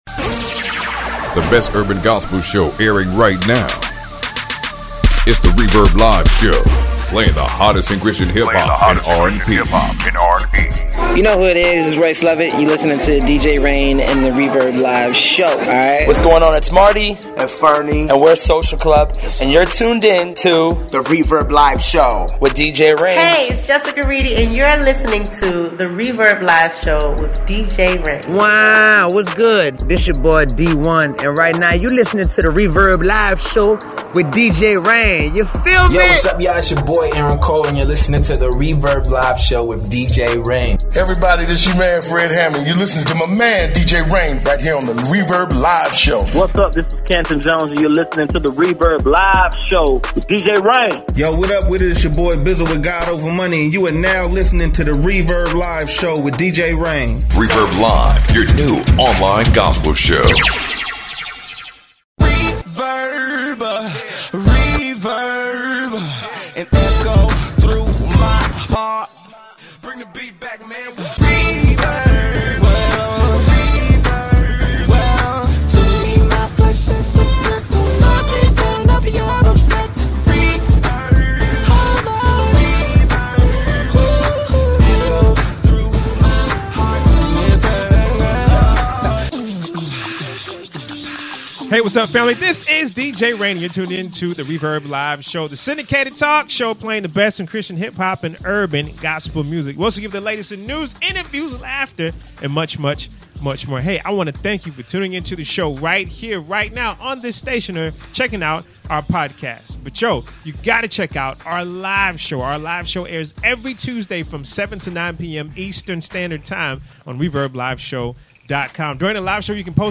We will let you hear the heated debate on pastors owning luxry vehicles. Pastor Joel Osteen was spotted driving a $300k Ferrari.